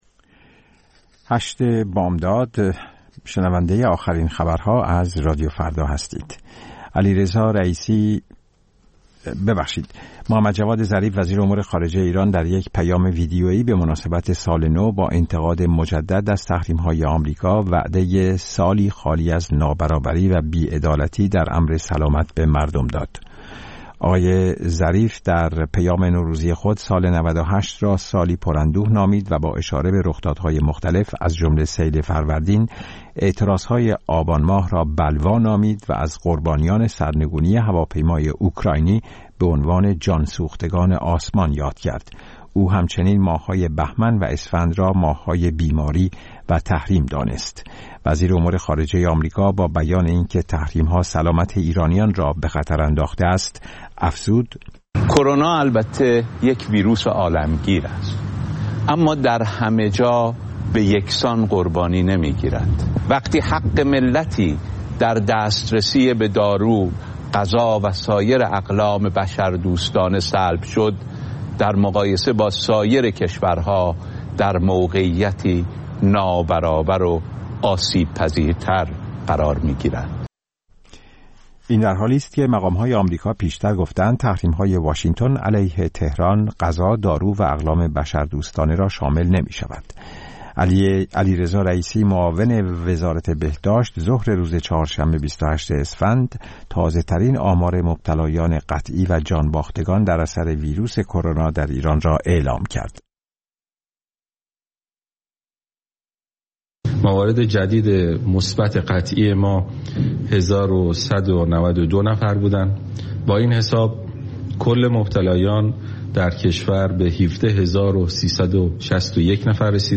اخبار رادیو فردا، ساعت ۸:۰۰